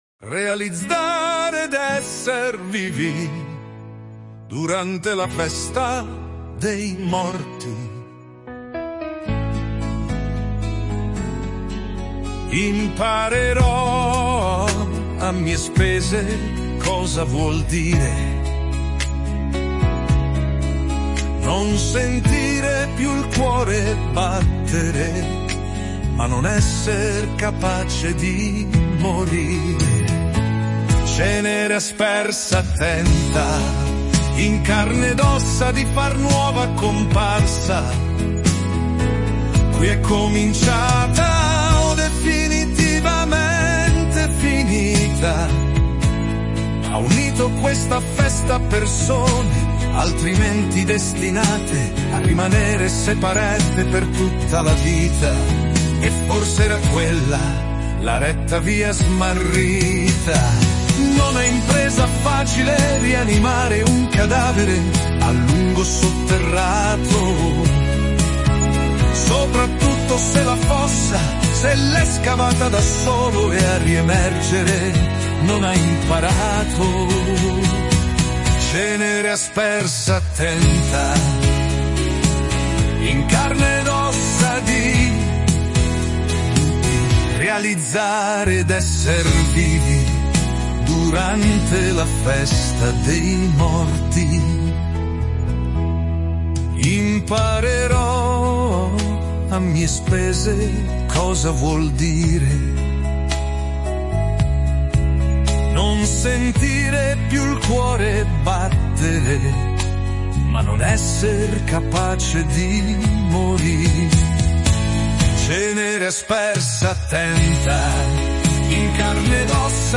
Great singing voice - great music.